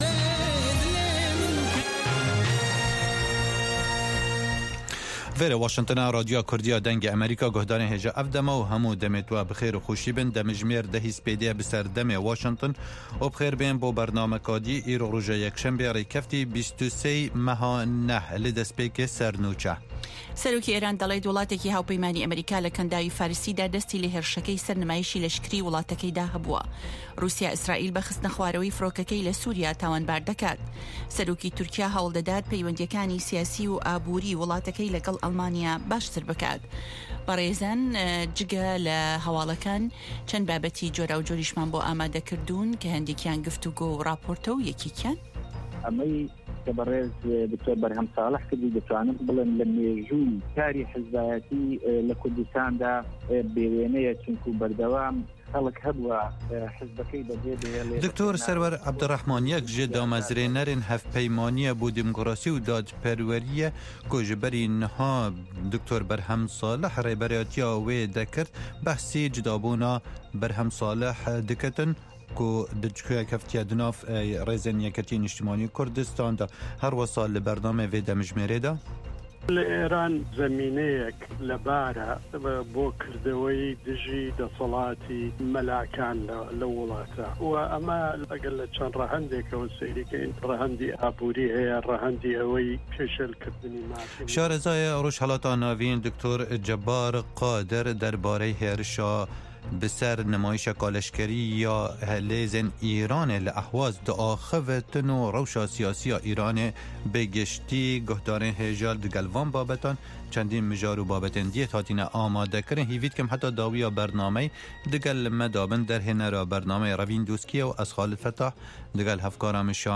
هه‌واڵه‌کان ، ڕاپـۆرت، وتووێژ، مێزگردی هه‌فته‌.